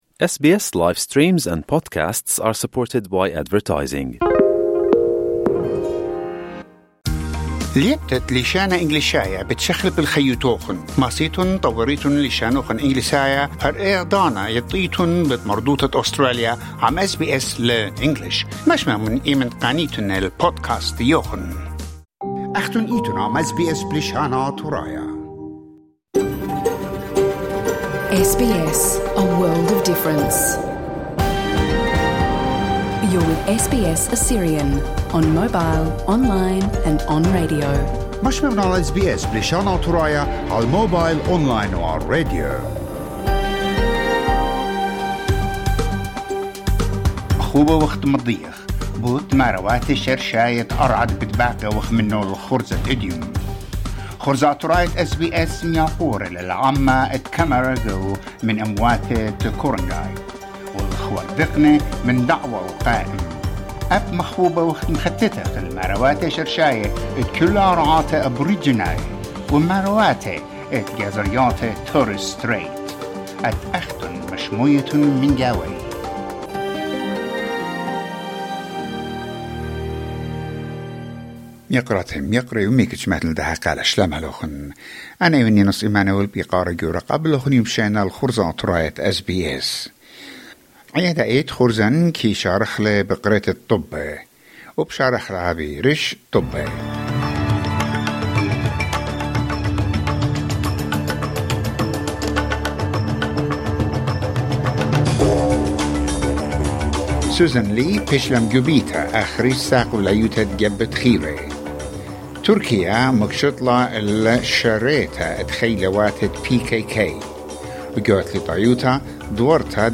In this episode: Interview with Mar Emil Nona about the Pope election.